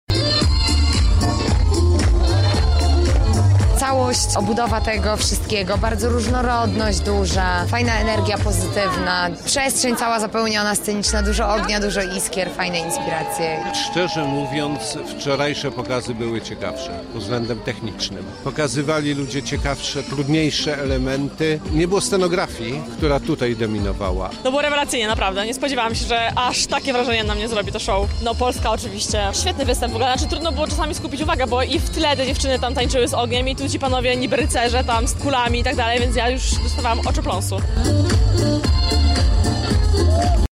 Na miejscu była nasza reporterka: